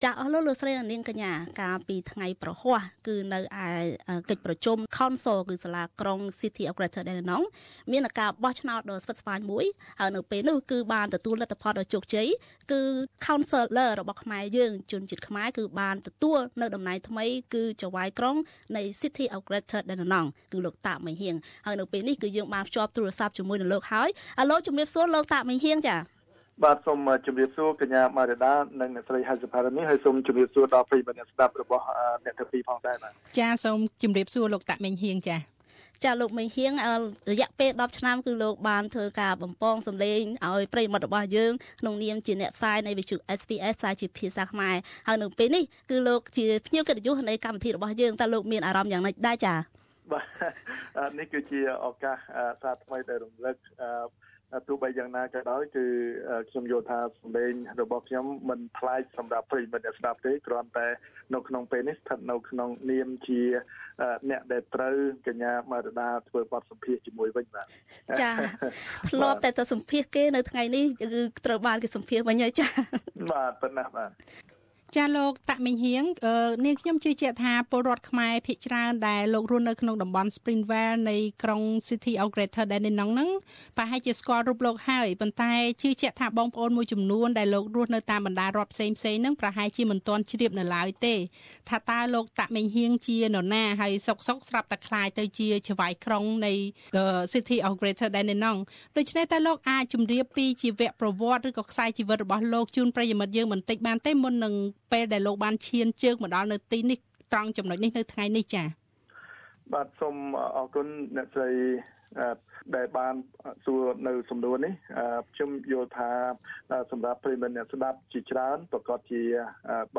លោកមានគោលដៅនិងការតាំងចិត្តលើមុខតំណែងថ្មីយ៉ាងណា? សូមស្តាប់កិច្ចសម្ភាសន៍ពិសេសនេះលំអិត៖